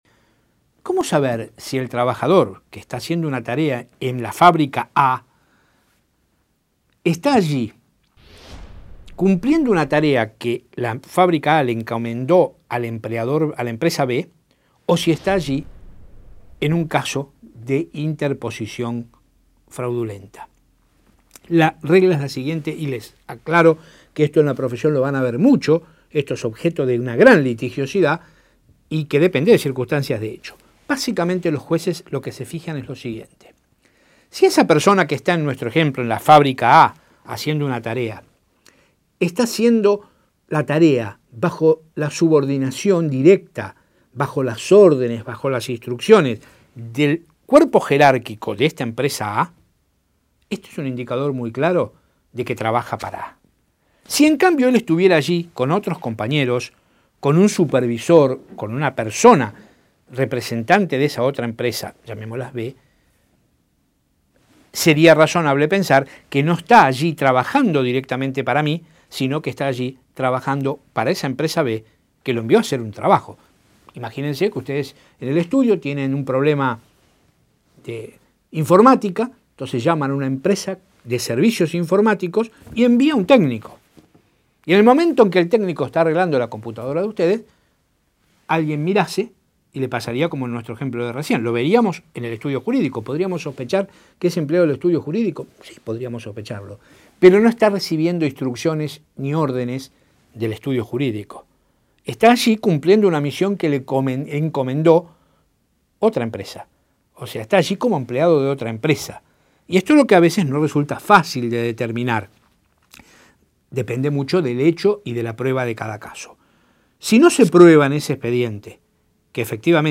Audio de la clase